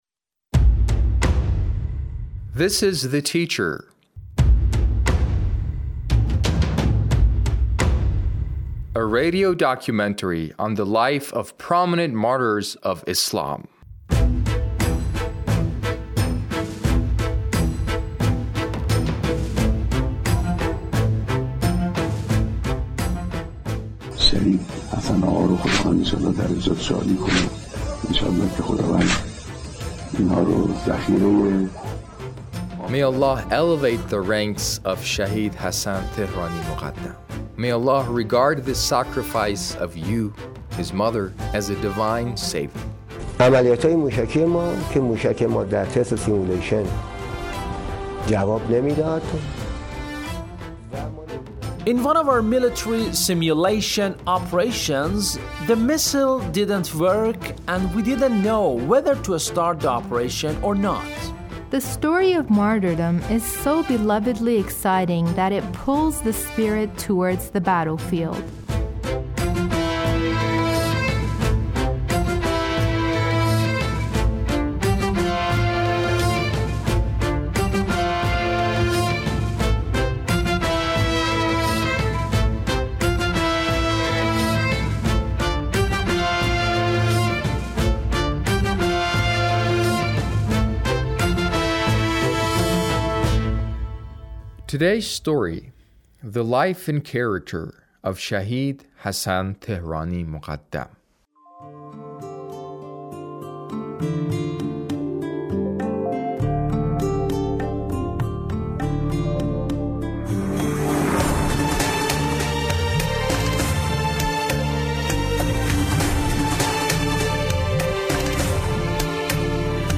A radio documentary on the life of Shahid Hassan Tehrani Moghaddam